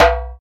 097 - Djembe.wav